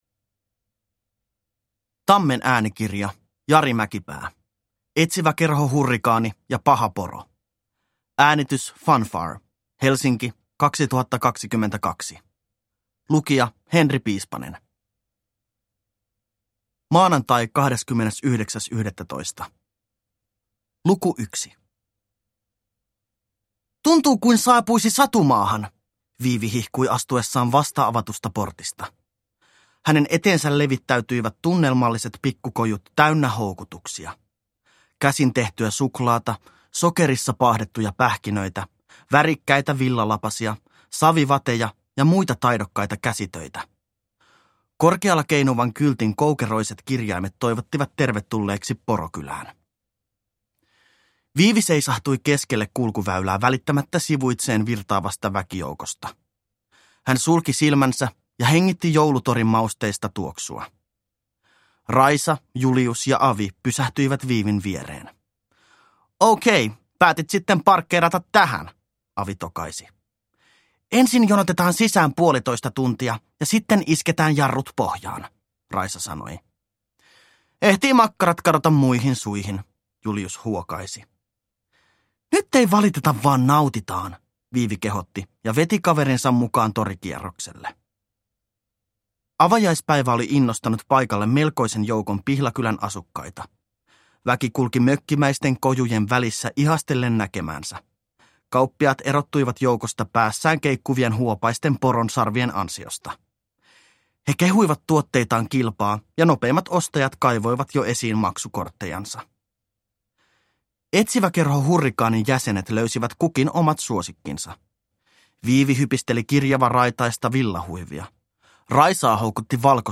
Etsiväkerho Hurrikaani ja paha poro – Ljudbok